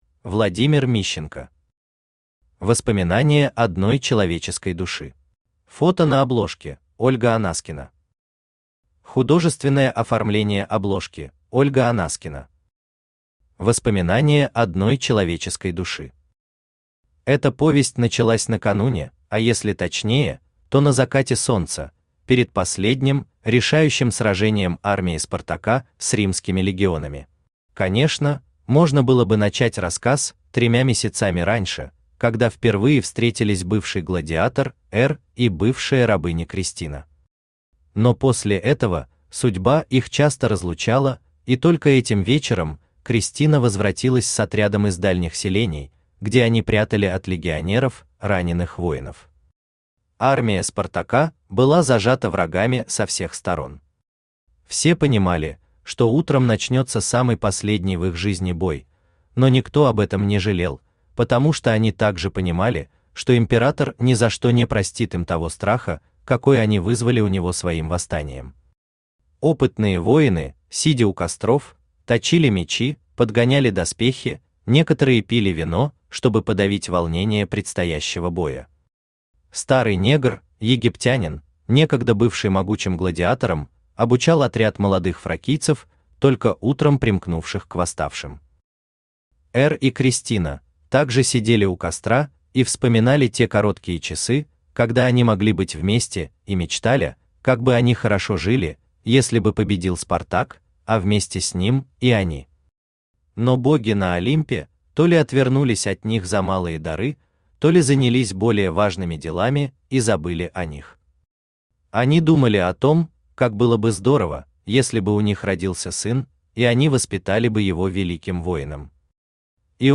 Аудиокнига Воспоминания одной человеческой души | Библиотека аудиокниг
Aудиокнига Воспоминания одной человеческой души Автор Владимир Мищенко Читает аудиокнигу Авточтец ЛитРес.